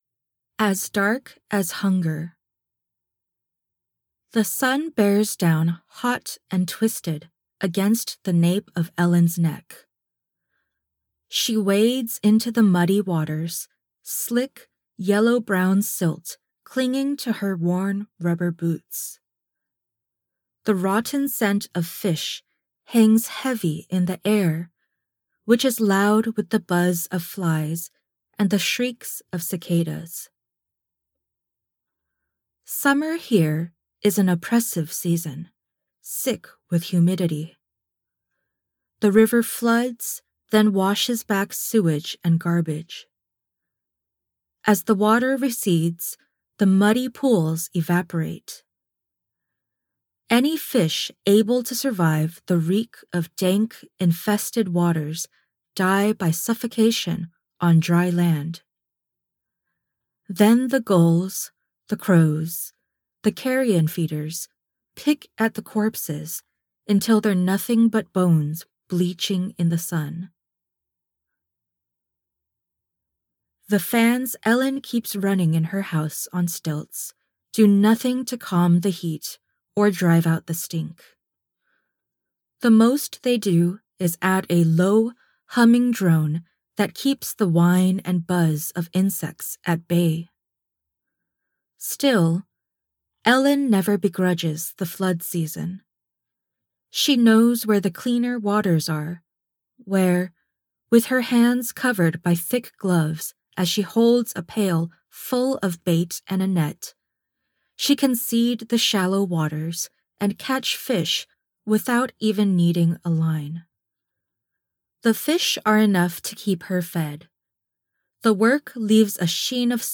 a novella